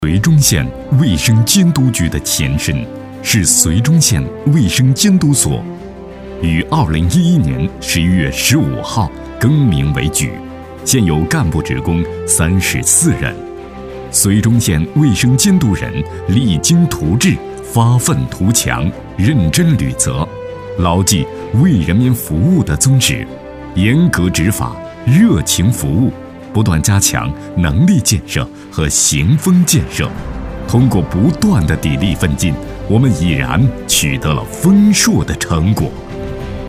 激情力度 企业专题,人物专题,医疗专题,学校专题,产品解说,警示教育,规划总结配音
优质男播音，擅长专题片，记录片、专题等不同题材。